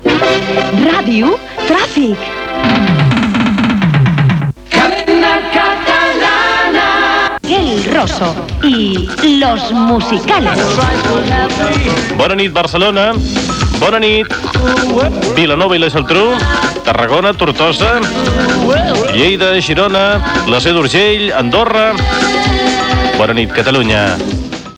Indicatiu del programa i salutació a les localitats connectades.
Musical